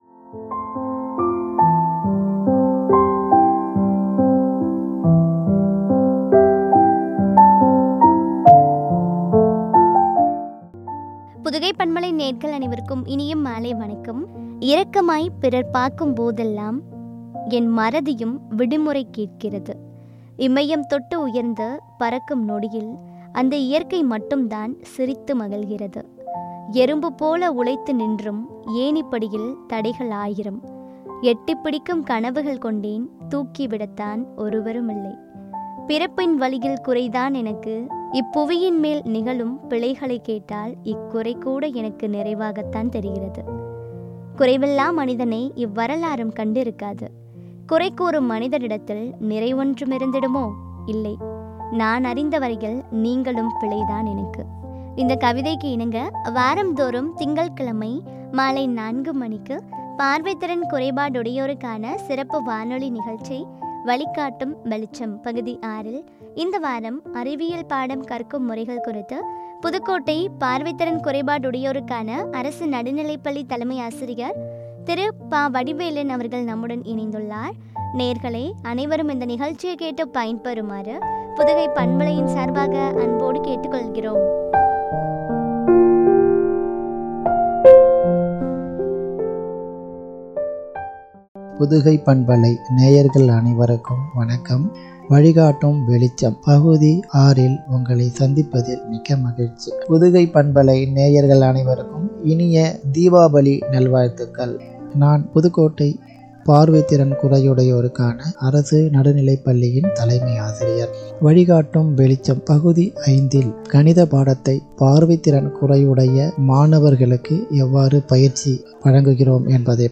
பார்வை திறன் குறையுடையோருக்கான சிறப்பு வானொலி நிகழ்ச்சி
” அறிவியல் பாடம் கற்கும் முறைகள்” குறித்து வழங்கிய உரையாடல்.